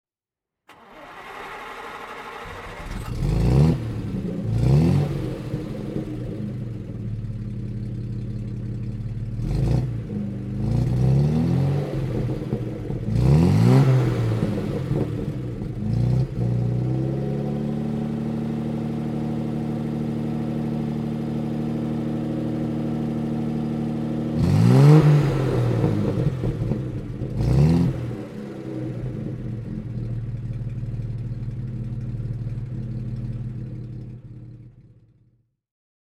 Jaguar Mk 2 2,4 Litre (1964) - Starten und Leerlauf
Jaguar_Mk_2_24.mp3